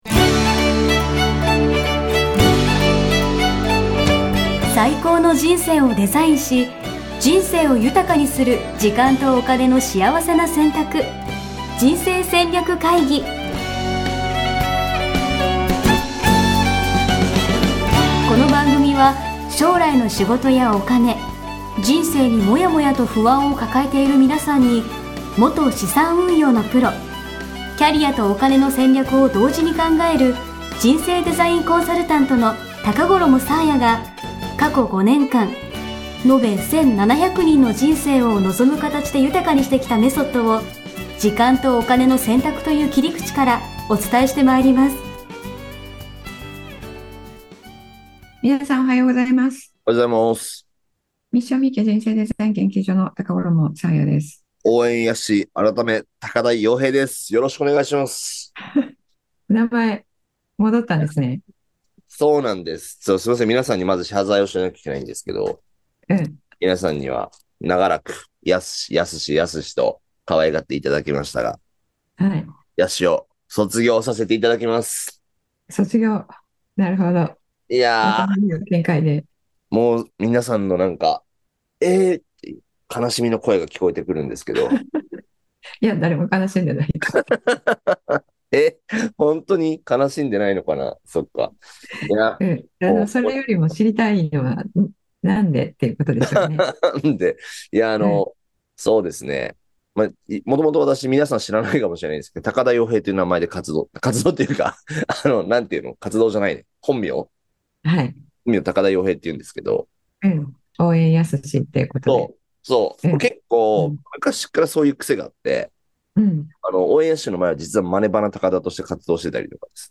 ※一部音声に乱れがあり聴きづらくなっておりますが、何卒ご容赦ください